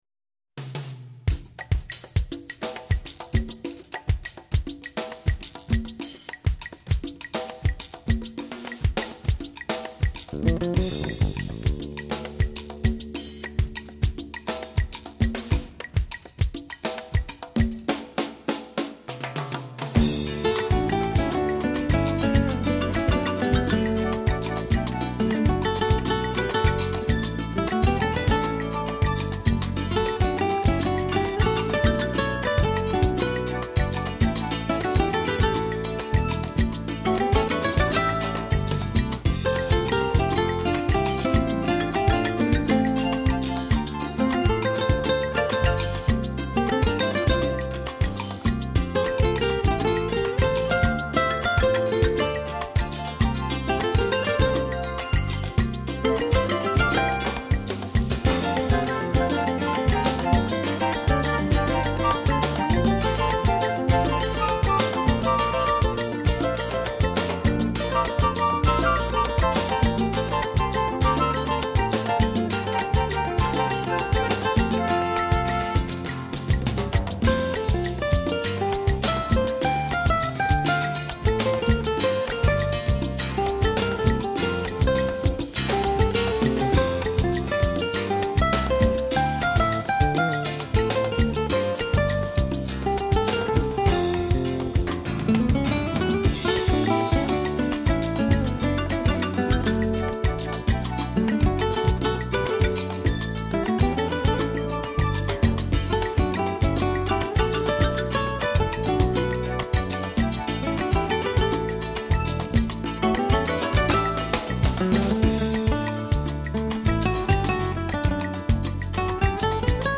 位拉丁美洲乐手演出木吉他，再以鼓和合成器等乐器伴奏，乐曲多属轻快
热情的南美音乐，听起来但觉身心畅快，顿时消除精神紧张。
此碟录音水准奇高，吉他音乐通透，令人有美不胜收之感。
别靓，合成器营造的高音乐声鲜艳耀目！